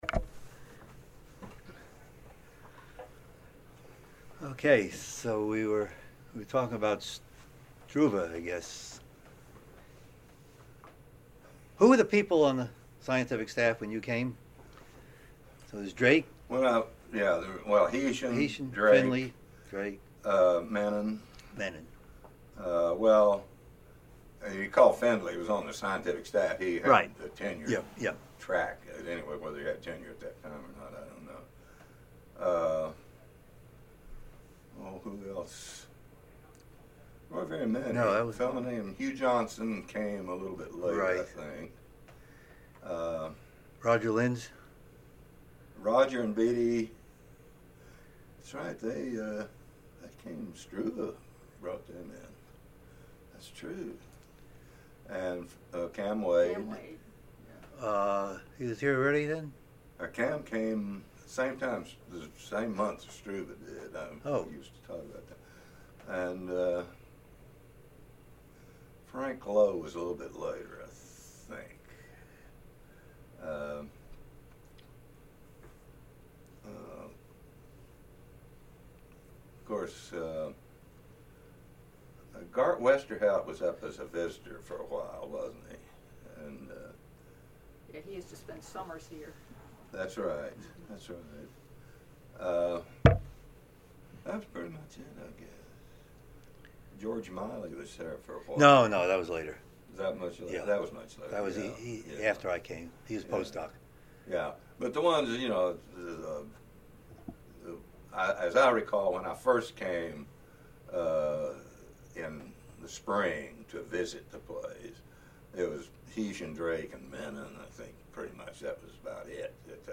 Oral History
Location Charlottesville, VA